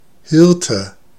Ääntäminen
Synonyymit drive dazzle herdsman Ääntäminen US UK : IPA : /hɜːd/ Haettu sana löytyi näillä lähdekielillä: englanti Käännös Konteksti Ääninäyte Substantiivit 1.